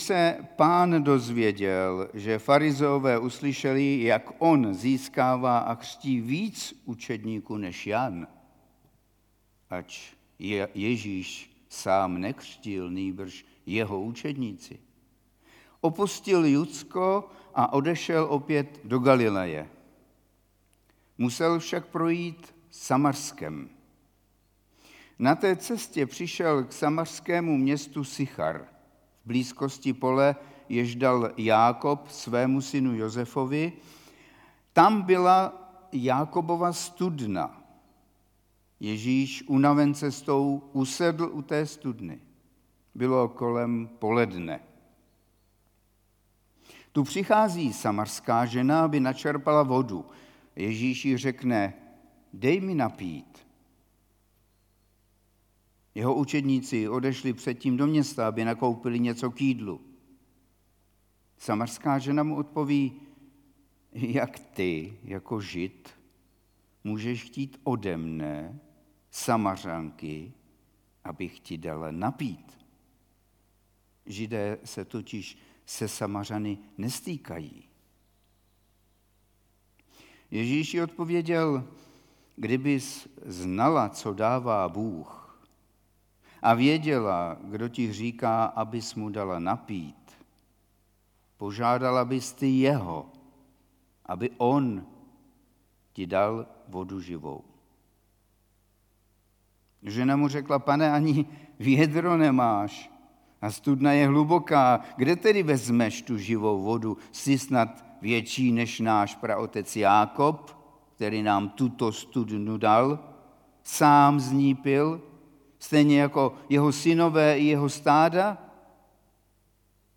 8. kázání ze série Záblesky slávy (Jan 4,1-26)
Kategorie: Nedělní bohoslužby